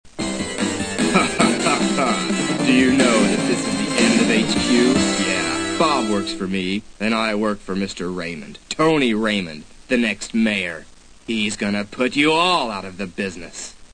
And here's some of the wonderful voice acting.